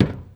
Footstep_Metal 05.wav